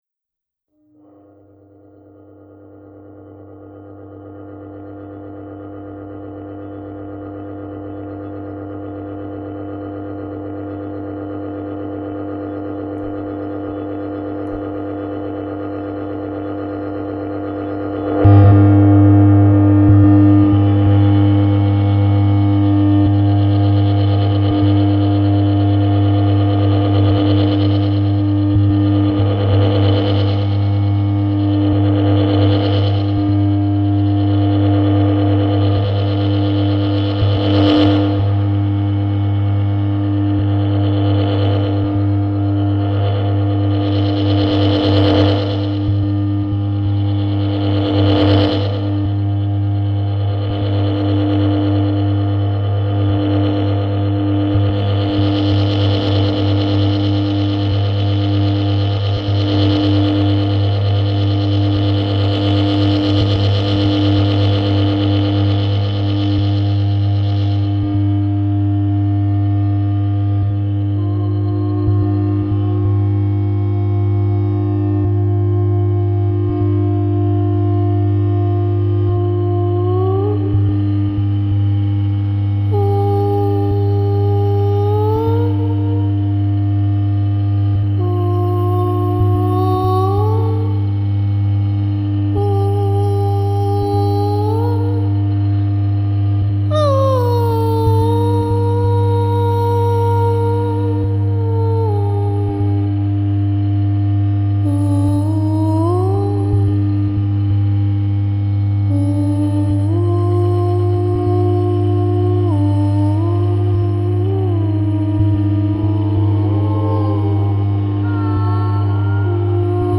Sound art
speaker feedback instrument + voice